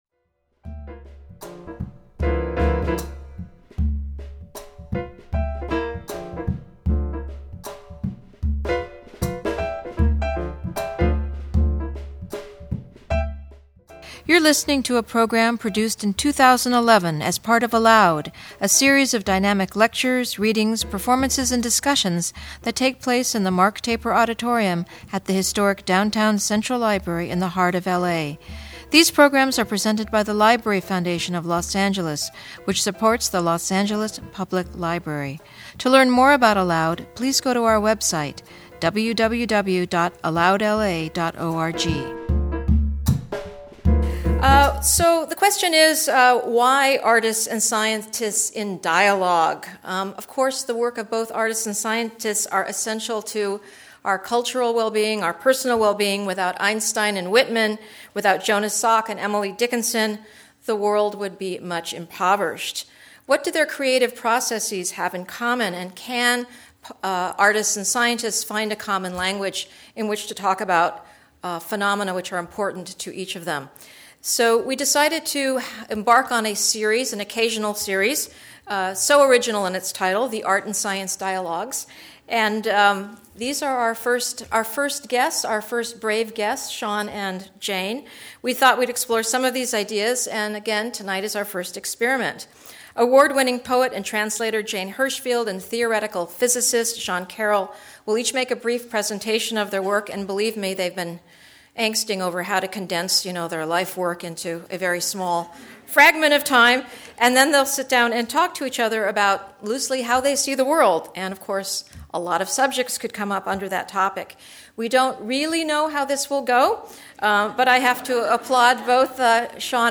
Hirshfield and Carroll---both at the vanguard of their disciplines-- discuss different (and perhaps similar) points of entry into the realm of observation and metaphor.